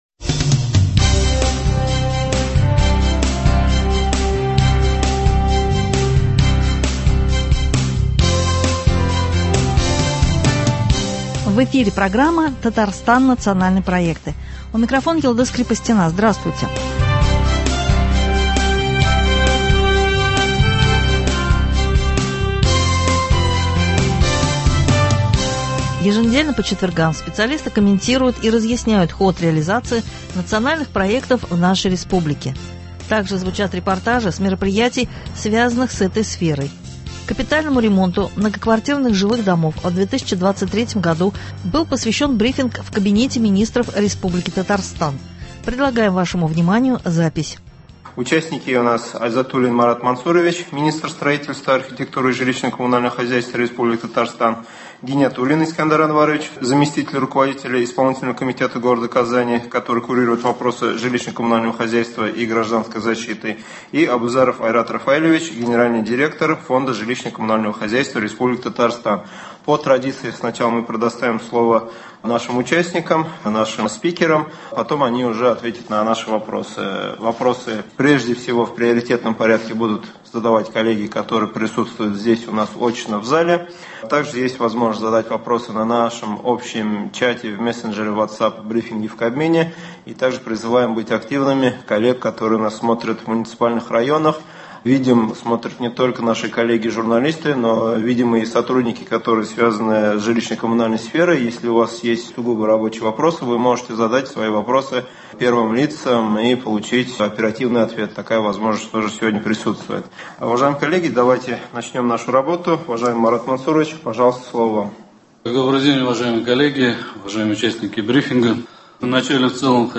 Еженедельно по четвергам специалисты комментируют и разъясняют ход реализации Национальных проектов в нашей республике. Также звучат репортажи с мероприятий, связанных с этой сферой.
Капитальному ремонту многоквартирных жилых домов в 2023 году был посвящен брифинг в КМ РТ, предлагаем вашему вниманию запись.